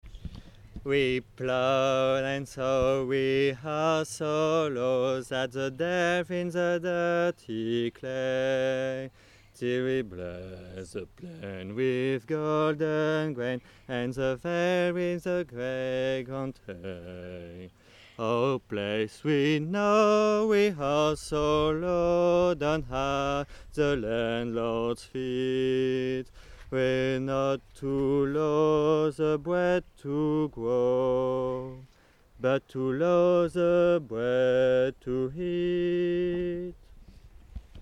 Music mid-19th century, England
Voix 4 (basse)
Song-of-the-lower-class-Voix-4.mp3